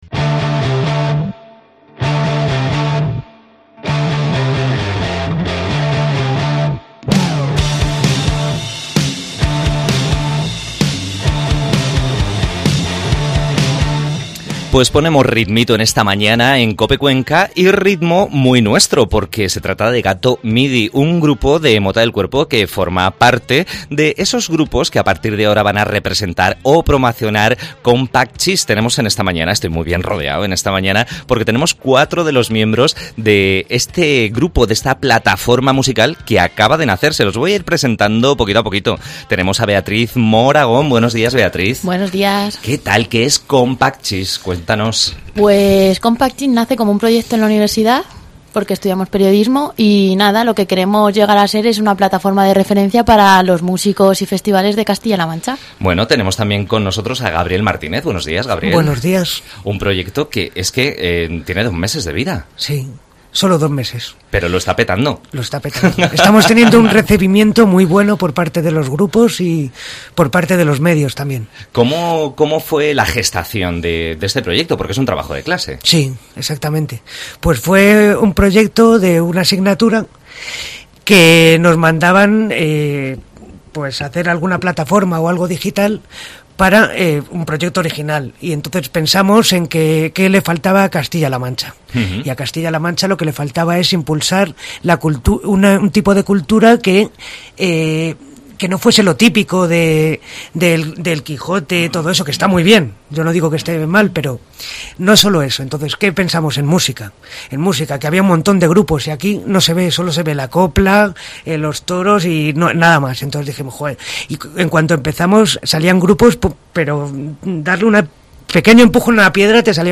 AUDIO: Entrevista a cuatro de los cinco creadores de la "red musical" castellanomanchega Compactcheese.